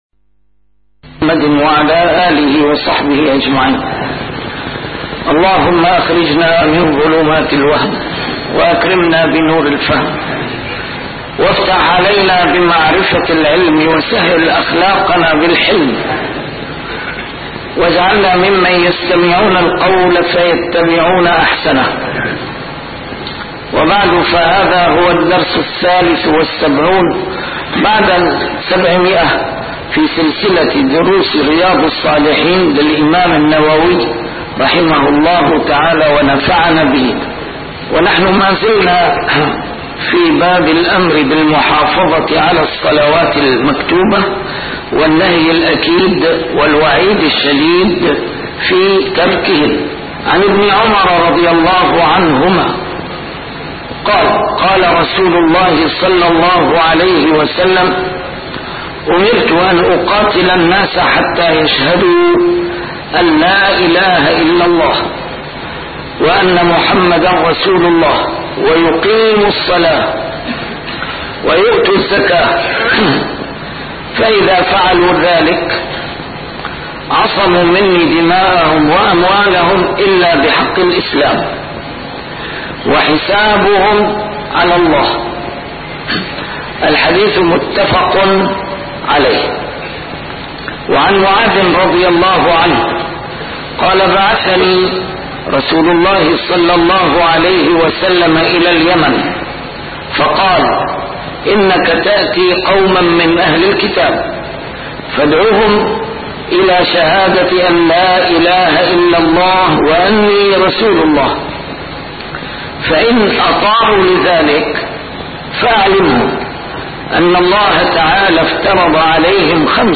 A MARTYR SCHOLAR: IMAM MUHAMMAD SAEED RAMADAN AL-BOUTI - الدروس العلمية - شرح كتاب رياض الصالحين - 773- شرح رياض الصالحين: المحافظة على الصلوات المكتوبات